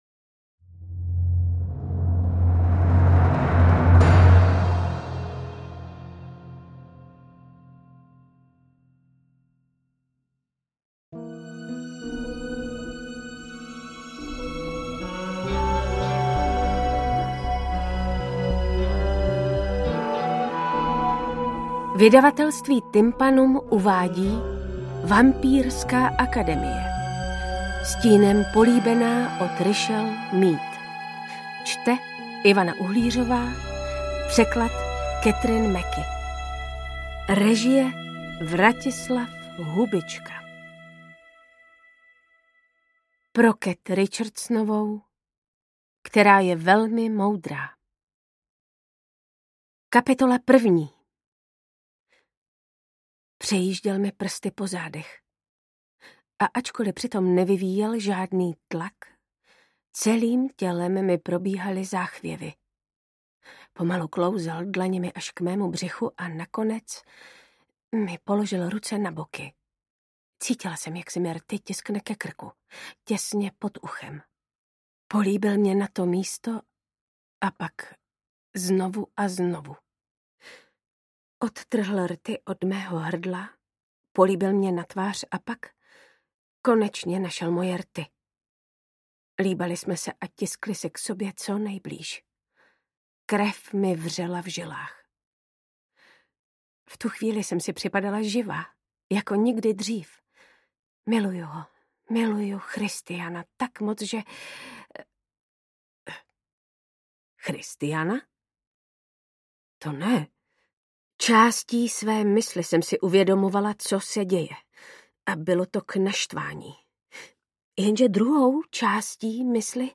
AudioKniha ke stažení, 50 x mp3, délka 16 hod. 9 min., velikost 893,0 MB, česky